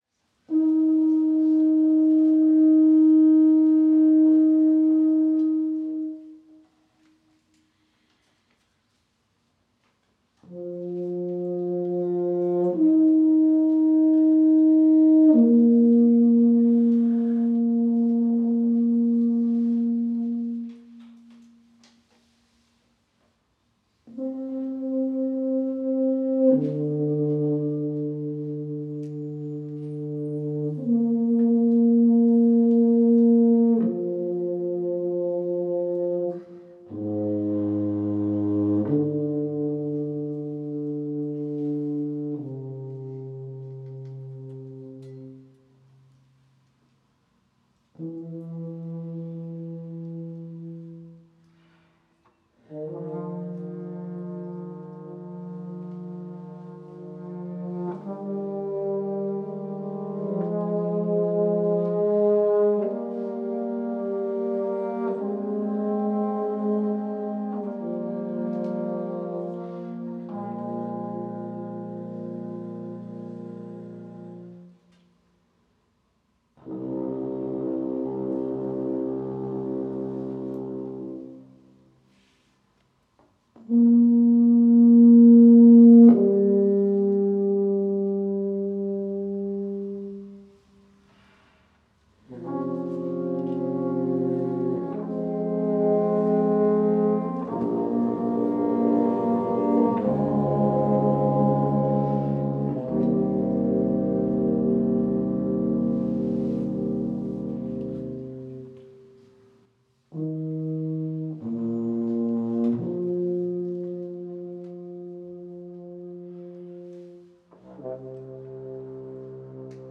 tuba
french horn
flugelhorn
recorded at KM28, Berlin on 11 September 2020
Inspired by the full dark sound of British brassbands